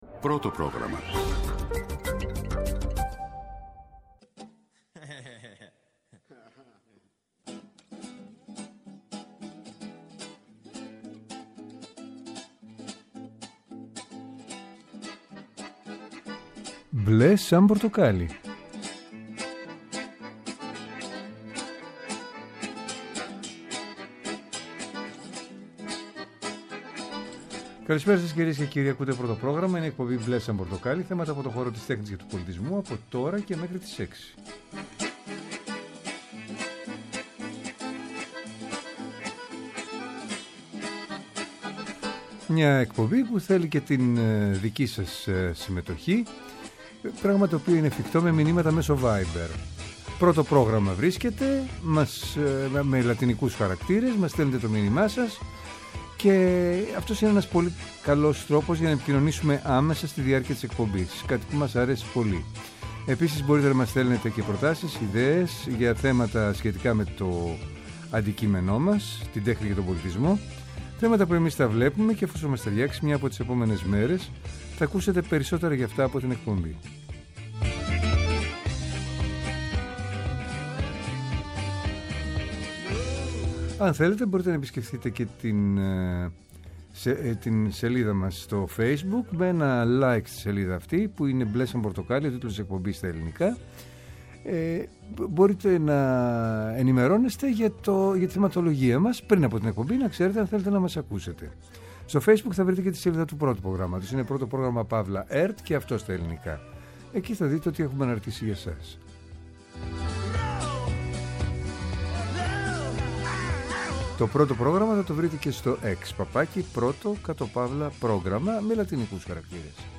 Θέατρο, κινηματογράφος, μουσική, χορός, εικαστικά, βιβλίο, κόμικς, αρχαιολογία, φιλοσοφία, αισθητική και ό,τι άλλο μπορεί να είναι τέχνη και πολιτισμός, καθημερινά από Δευτέρα έως Παρασκευή 5-6 το απόγευμα από το Πρώτο Πρόγραμμα. Μια εκπομπή με εκλεκτούς καλεσμένους, άποψη και επαφή με την επικαιρότητα.